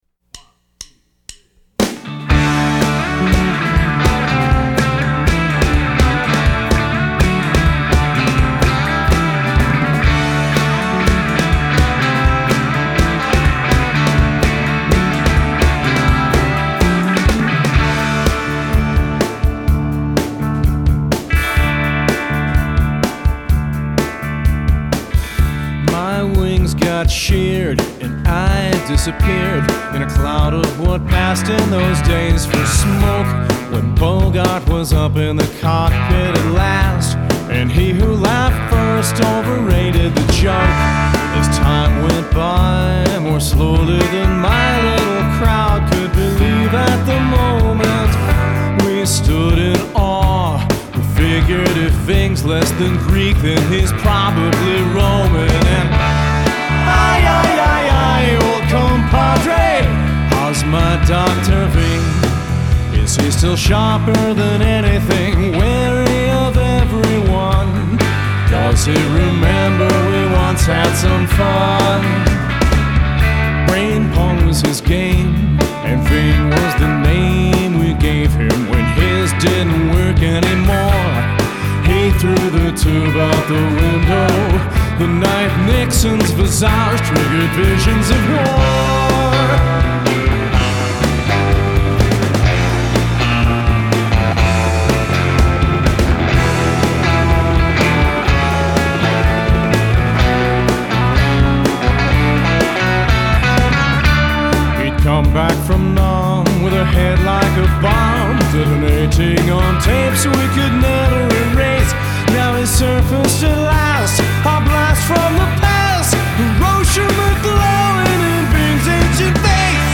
Helicopter swarm